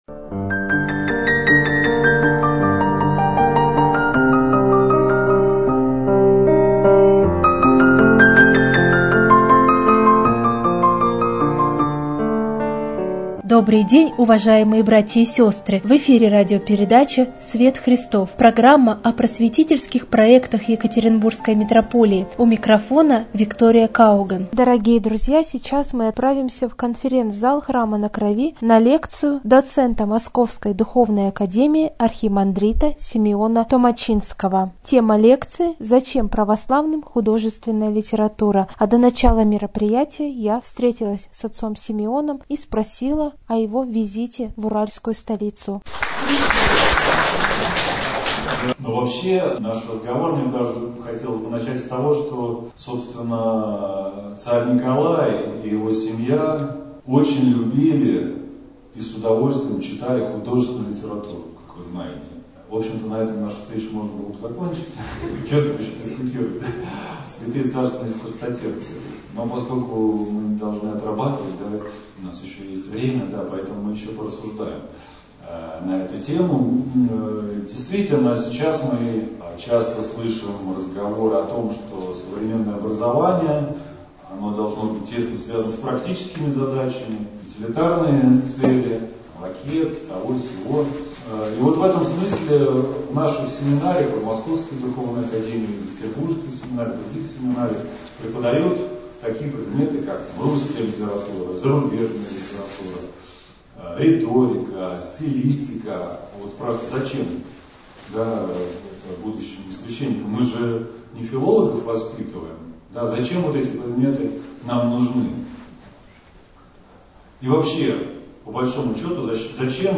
в Храме на Крови